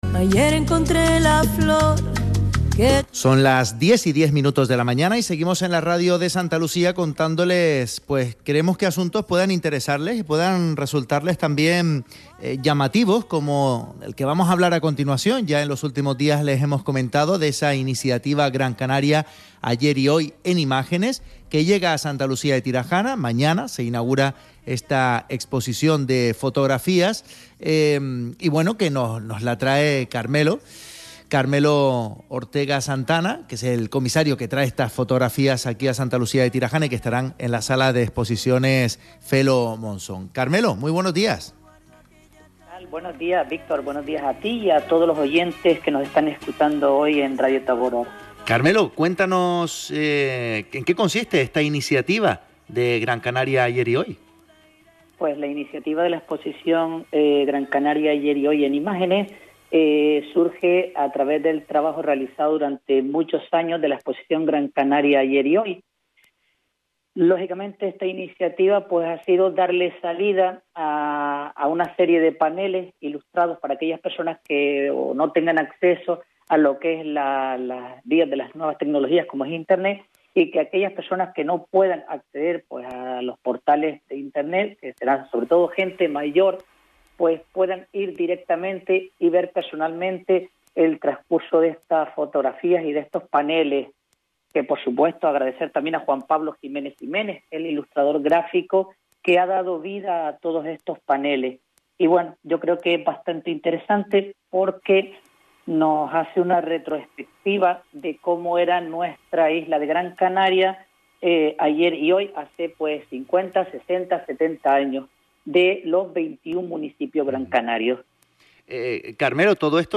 Entrevistado en emisoras de radio, sobre la p�gina Gran Canaria Ayer y Hoy y las exposiciones realizadas